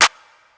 Clap(Dro).wav